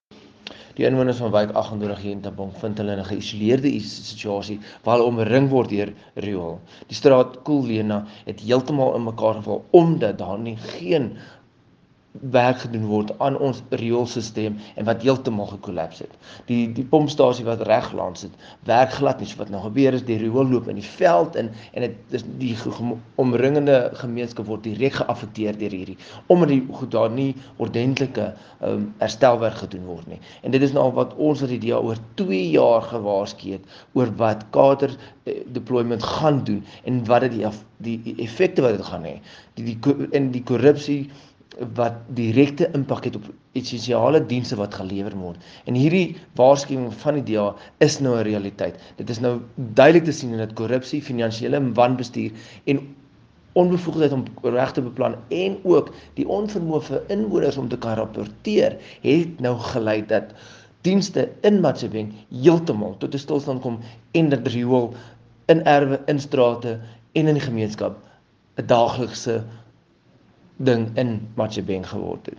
Afrikaans soundbites by Cllr Igor Scheurkogel and